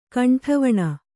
♪ kaṇṭhavaṇa